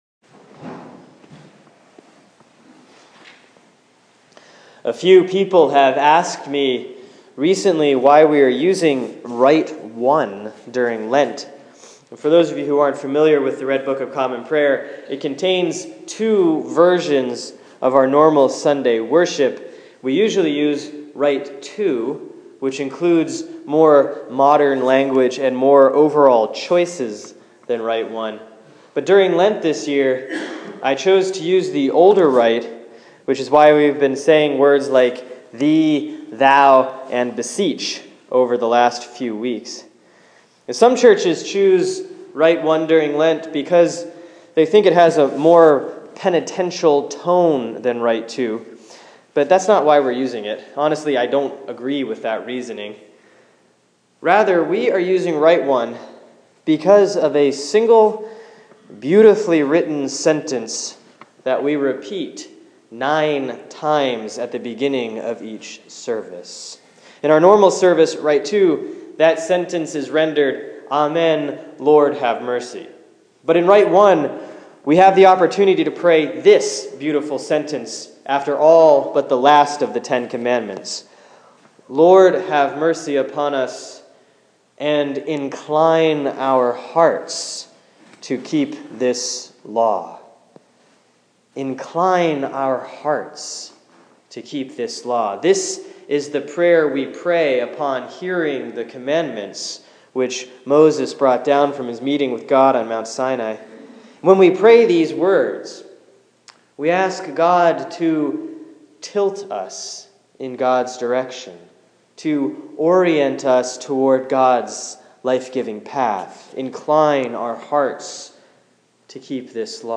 Sermon for Sunday, March 8, 2015 || Lent 3B || Exodus 20:1-17